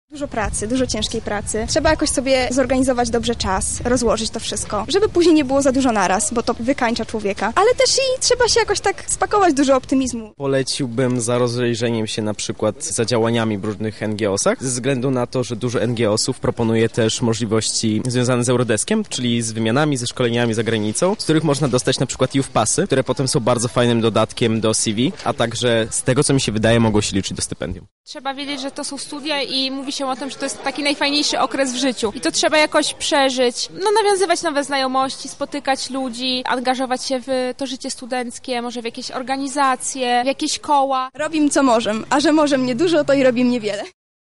[SONDA] Jakie dobry rady mają dla przyszłych studentów doświadczeni żacy?
Żacy i żaczki Uniwersytetu Marii Curie-Skłodowskiej podzielili się dobrymi radami z osobami, które w październiku rozpoczną studia: